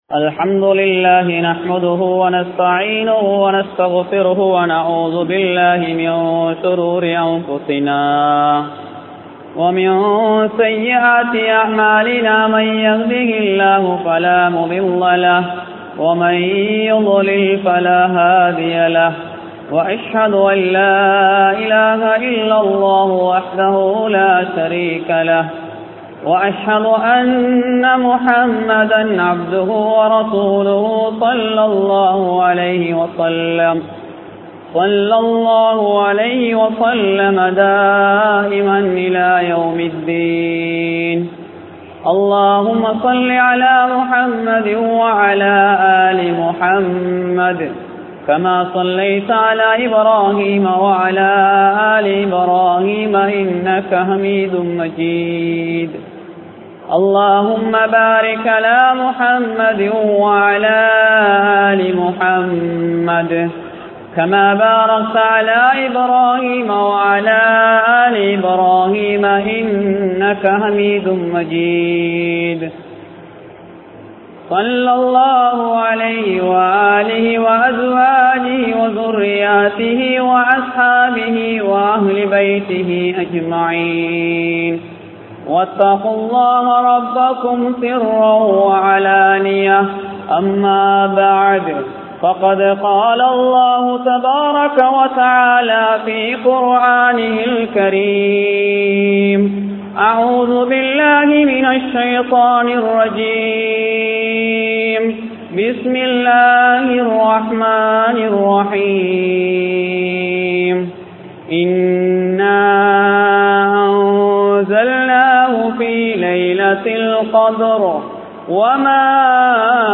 Lailaththul Kathrin Sirappuhal (லைலத்துல் கத்ரின் சிறப்புகள்) | Audio Bayans | All Ceylon Muslim Youth Community | Addalaichenai
Kurunegala, Thalgaspitiya Jumua Masjidh